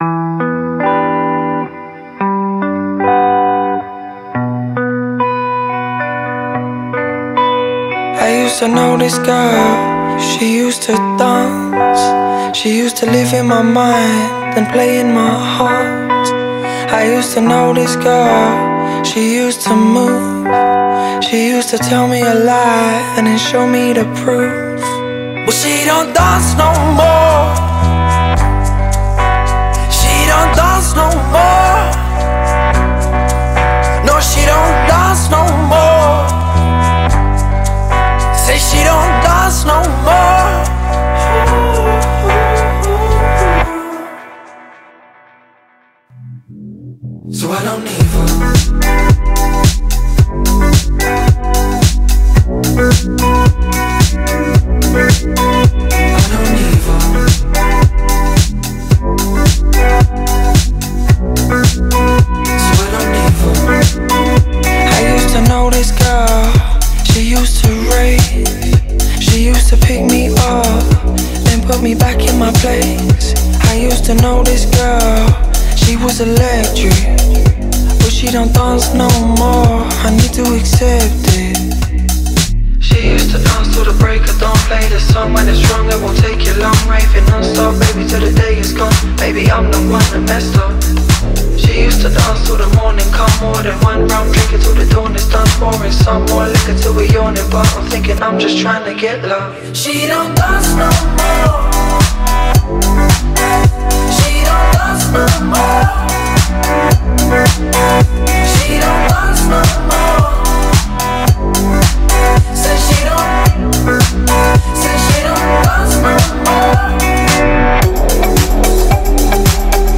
هیپ هاپ، پانک و رِیو